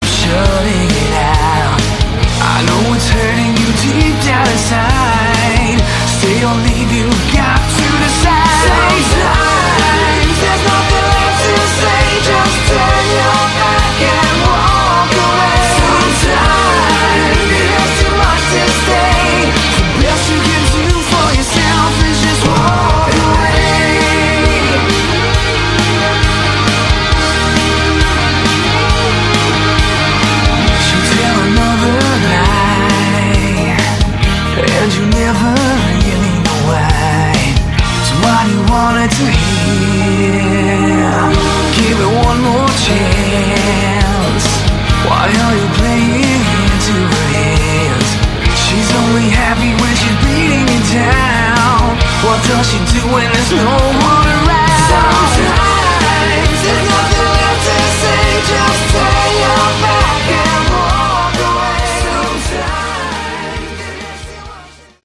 Category: Melodic Rock
vocals, guitars
bass, vocals
drums, keyboards